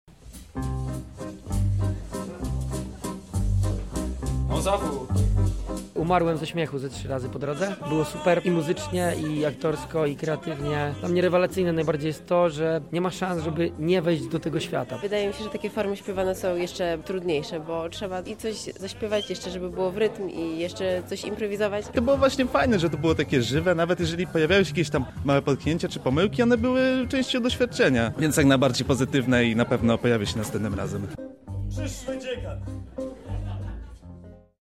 Muzyka, śmiech i zaskakujące puenty – za nami pierwszy wieczór pod znakiem improwizowanego musicalu.
Zamiast zwykłych improwizowanych scenek, Impro teatr Bezczelny zorganizował rozrywkę z muzyką na żywo w tle.
impro relacja